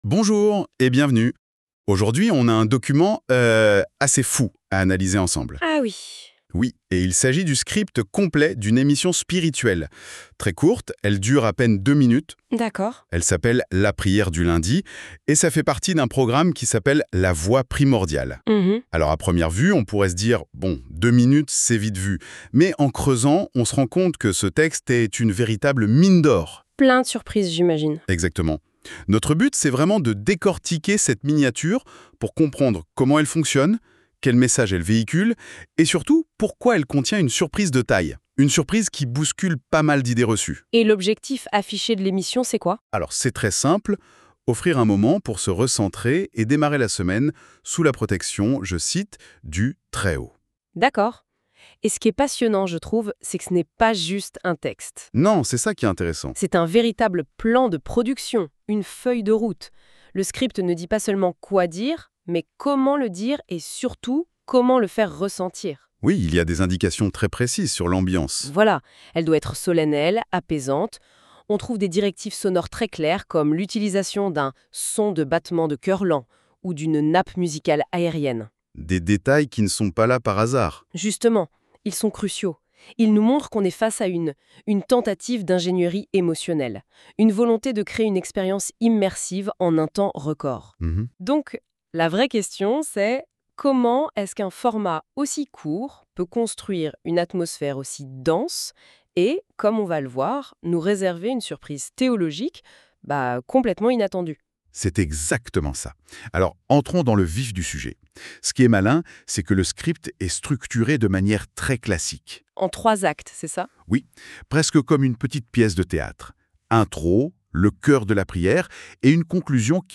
Ambiance : Solennelle, apaisante, spirituelle